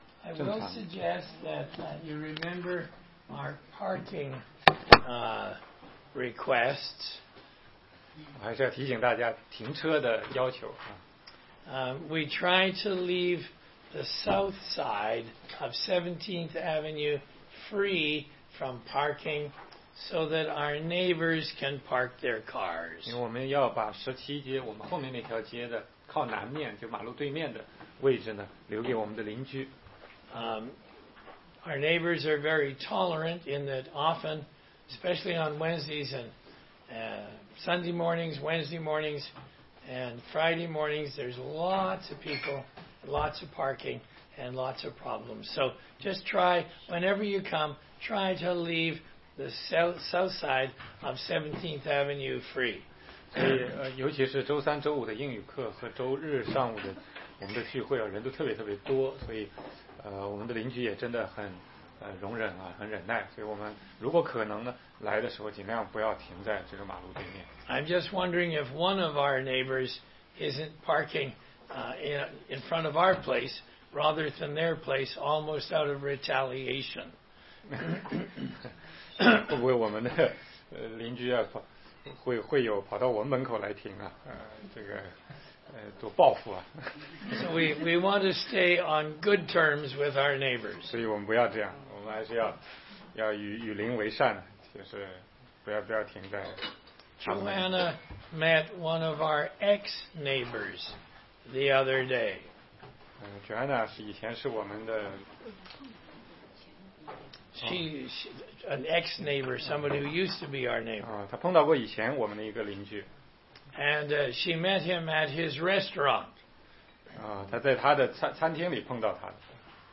16街讲道录音 - 哥林多前书8章1节-9章23节：如何使用你的自由